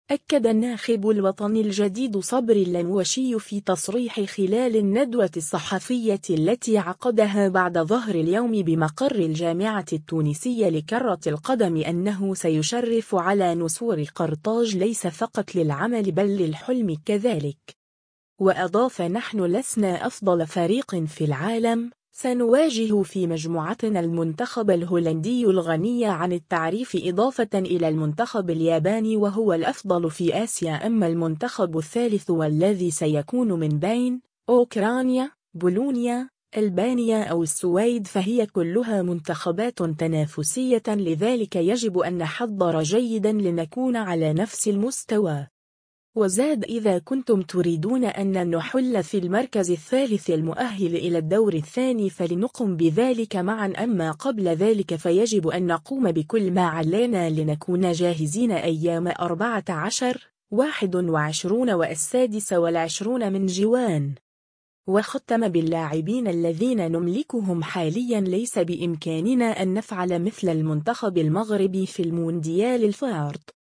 أكّد الناخب الوطني الجديد صبري اللموشي في تصريح خلال الندوة الصحفية التي عقدها بعد ظهر اليوم بمقر الجامعة التونسية لكرة القدم أنّه سيشرف على نسور قرطاج ليس فقط للعمل بل للحٌلم كذلك.